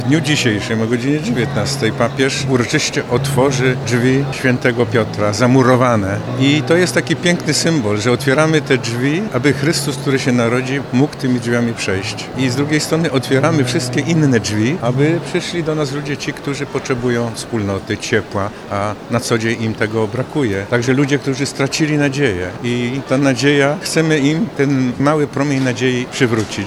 Dzielenie się opłatkiem, posiłek, a następnie kolędowanie. W Lublinie odbyła się Wigilia Caritas, która zgromadziła około 700 osób.